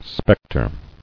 [spec·tre]